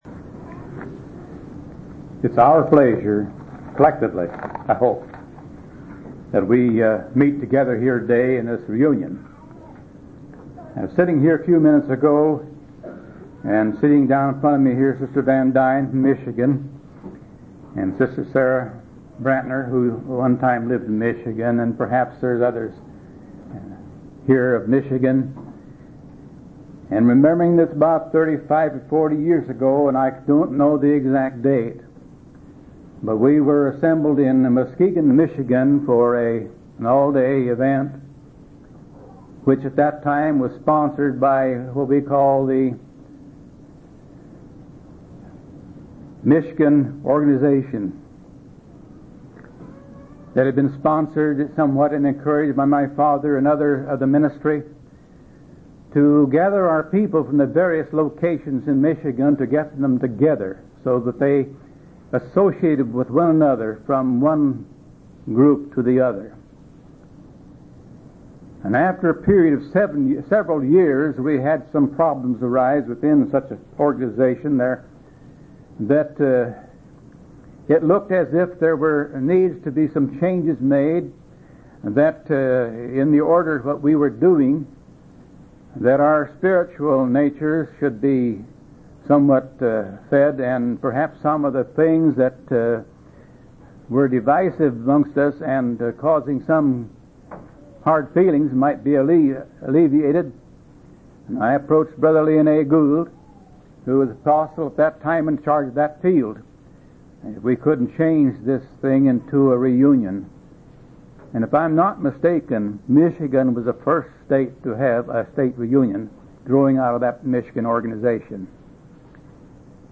8/16/1988 Location: Missouri Reunion Event